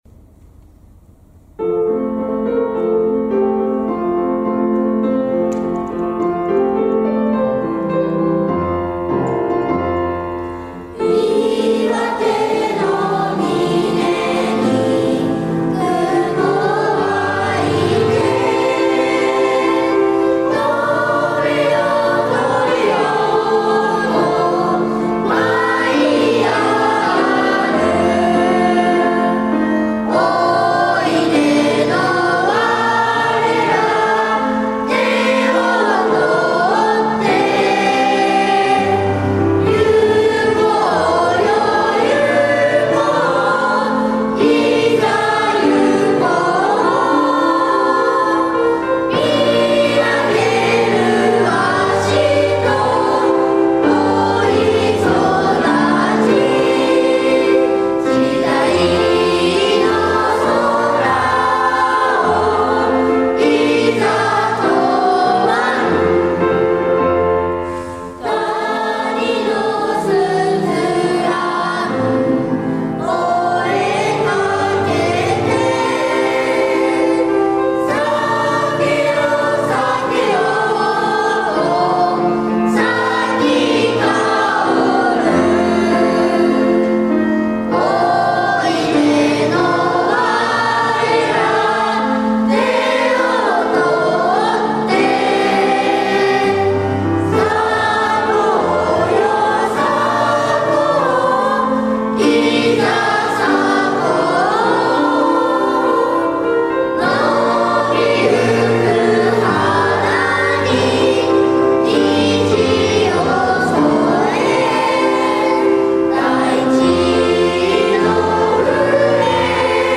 子どもたちは、大学生のお姉さんさんが演奏するじょうずなピアノ伴奏に合わせて、気持ちを込めて校歌を歌いました。
R6_1204_校歌_児童＋先生_ｴｺｰ.mp3